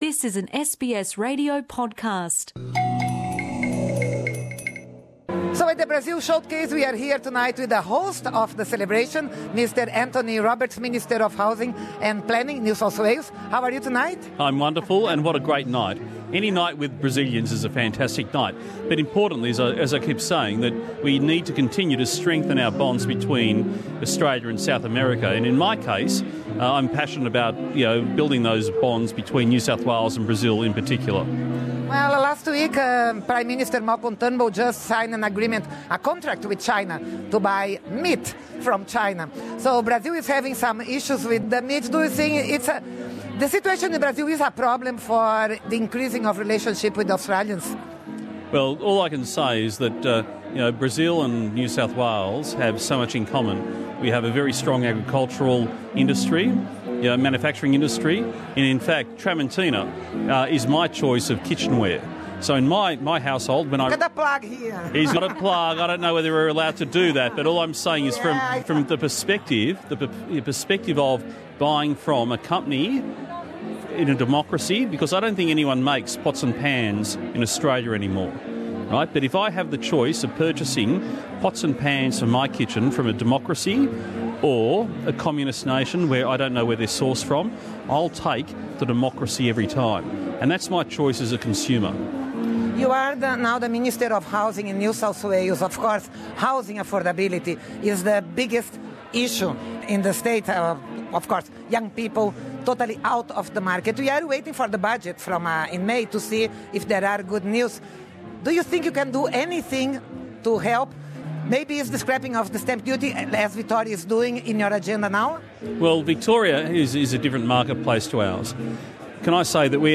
Com os preços exorbitantes das casas e apartamentos em Sydney, o ministro Roberts diz que novas medidas deverão ser anunciadas logo, especialmente no setor de oferta de moradias. O ministro da Habitação e Planejamento de Nova Gales do Sul, Anthony Roberts, disse ao Programa Português da Rádio SBS que a sua principal tarefa no governo é diminuir o problema do acesso à casa própria no estado, especialmente em Sydney.
Minister Anthony Roberts talking to SBS Portuguese Source